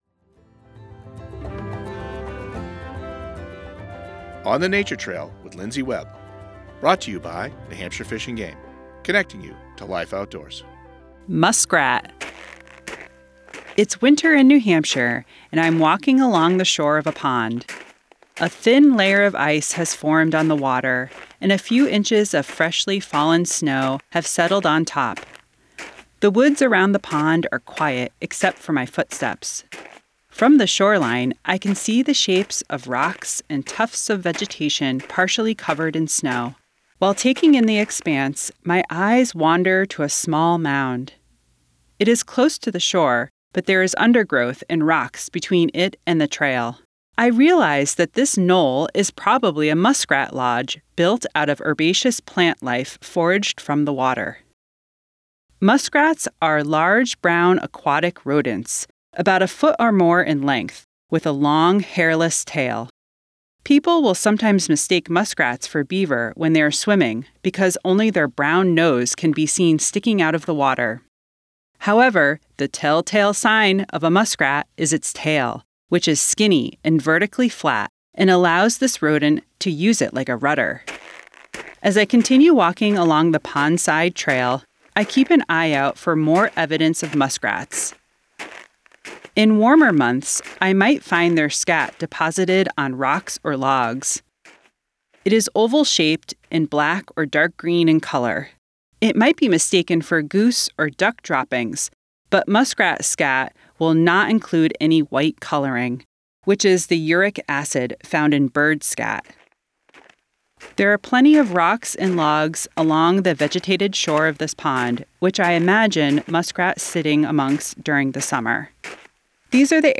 In this episode of On the Nature Trail, we take a quiet, snowy walk along a frozen New Hampshire pond, seeking out the subtle winter signs of the muskrat.